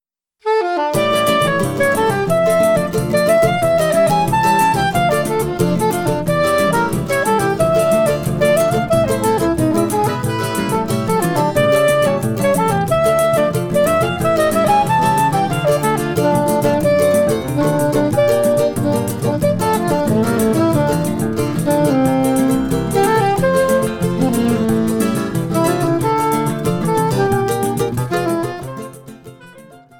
soprano saxophone
Choro ensemble